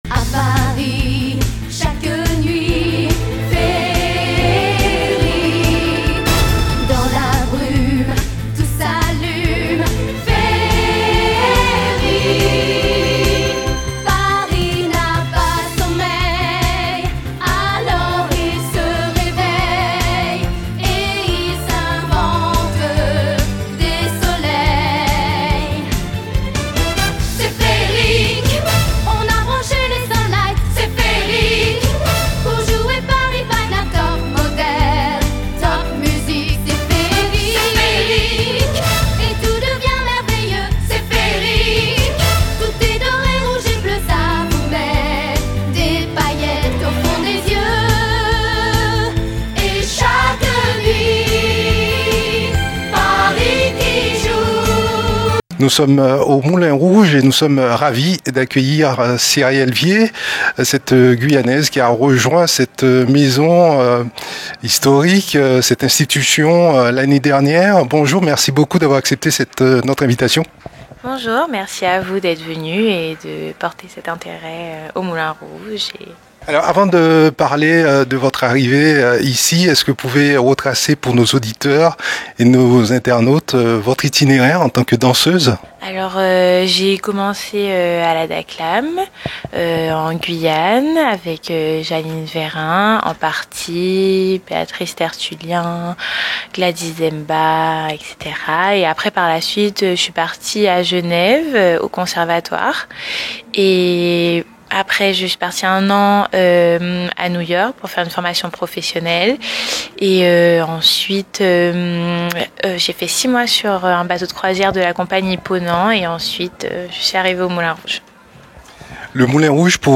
Elle répond aux questions de Radio Mayouri Campus.